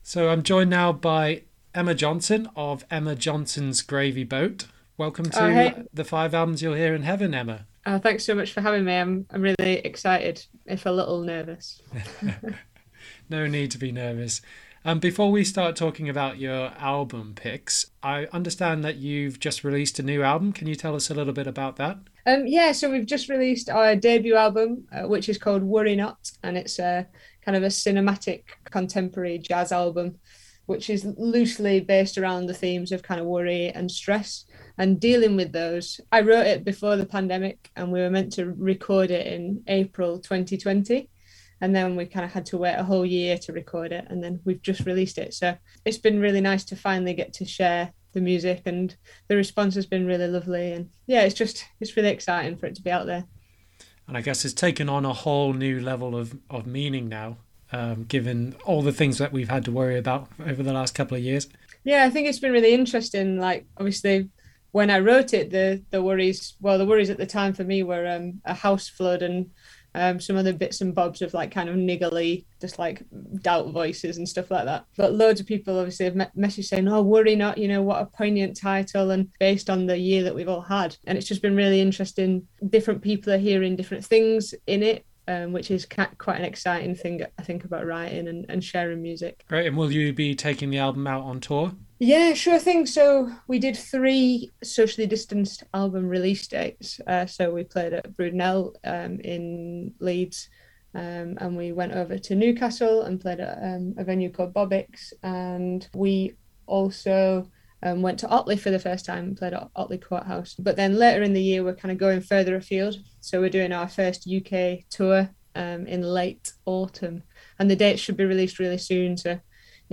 Have a listen to our chat below to find out which, plus her other album picks.